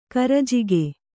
♪ karajigekāyi